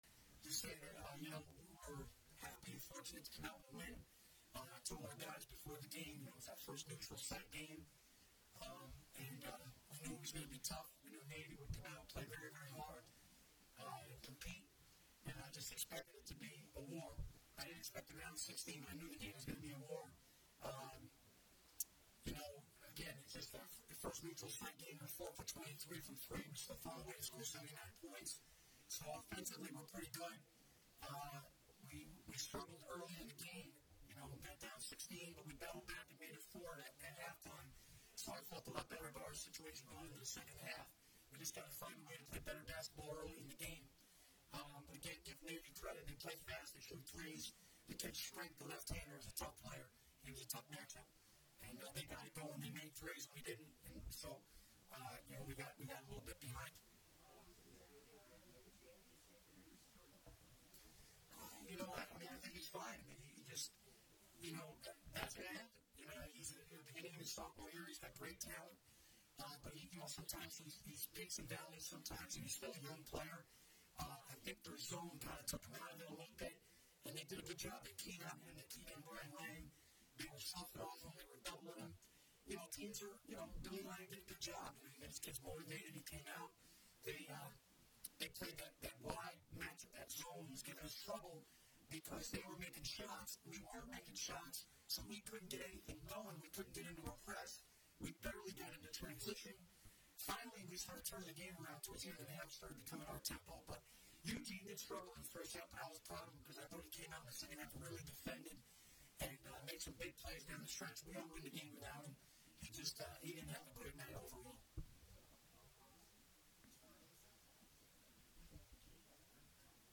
Postgame audio: Navy |